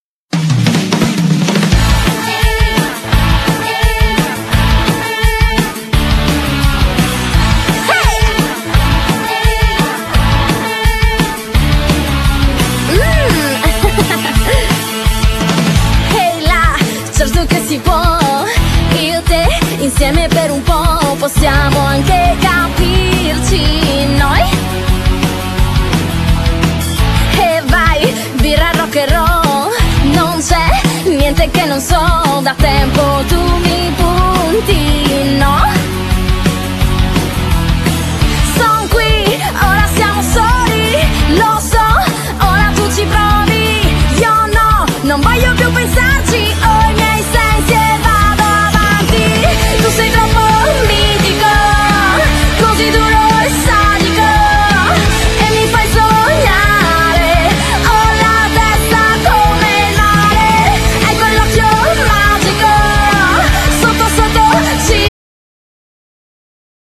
Genere : Punk/Pop